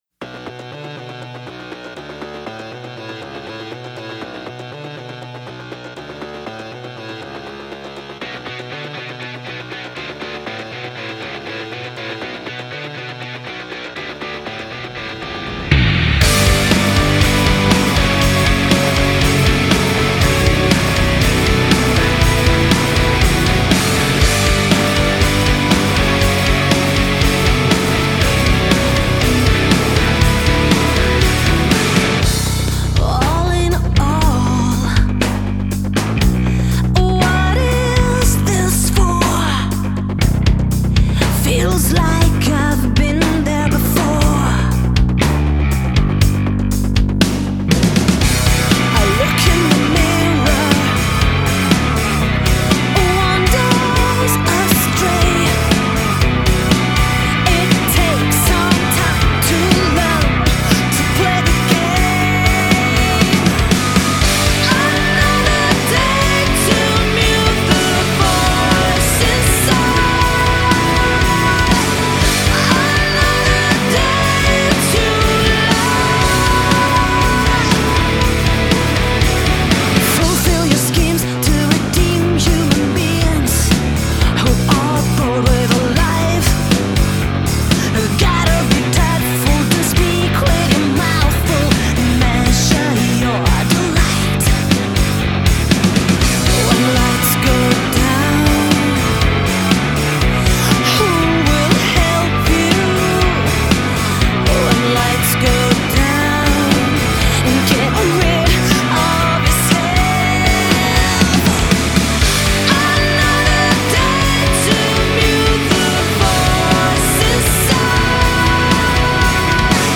Vocals
Guitars, Keyboard, Programming
Drums
Bass